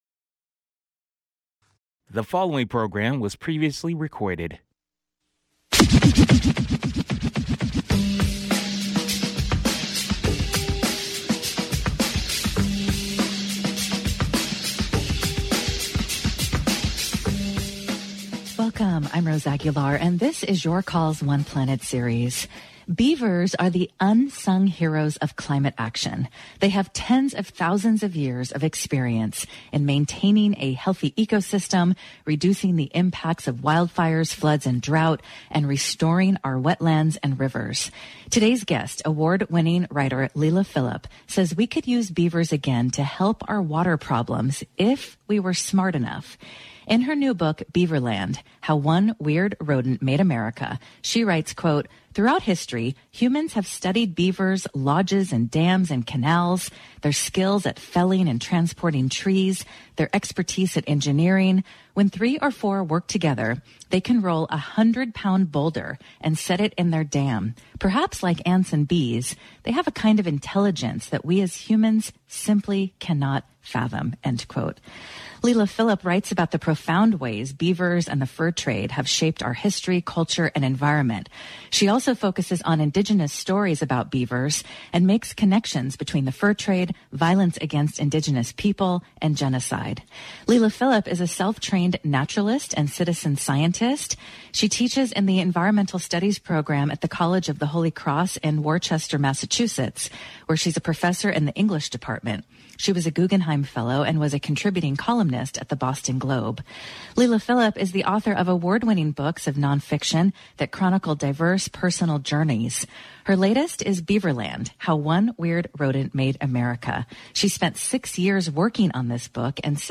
KALW's call-in show: Politics and culture, dialogue and debate.